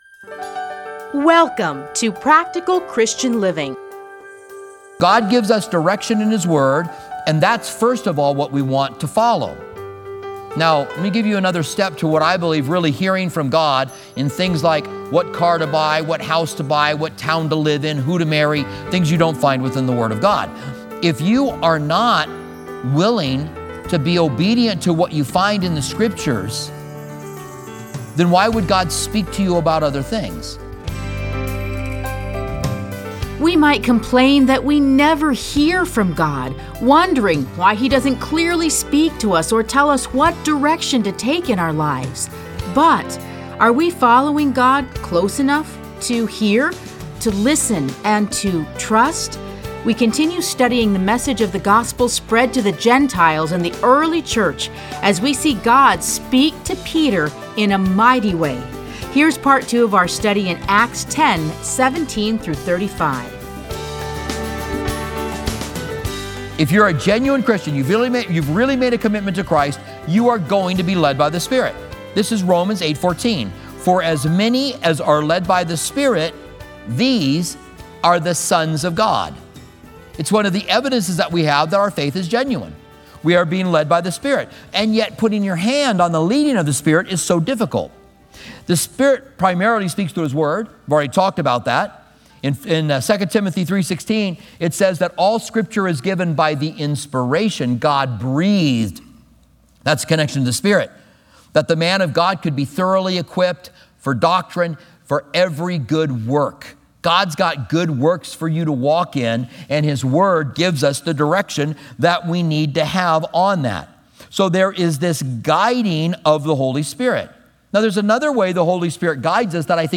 Listen to a teaching from Acts 10:17-35.